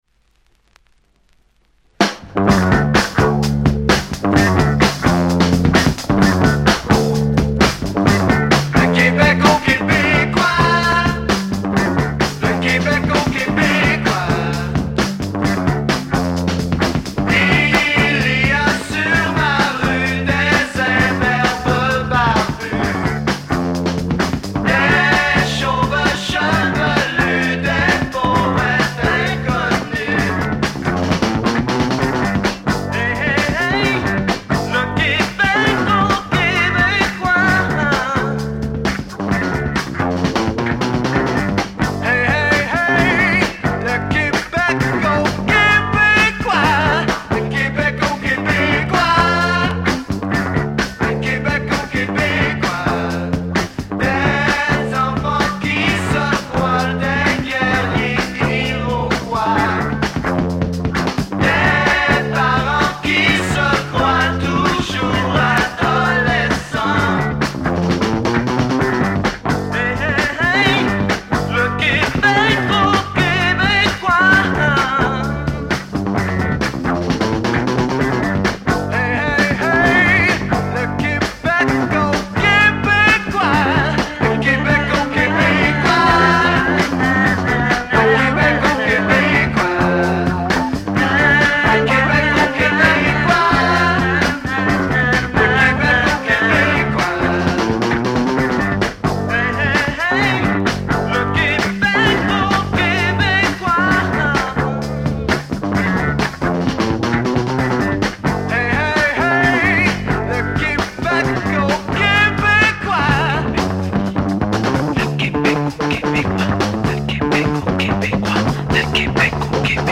Ça groove ! sourirezz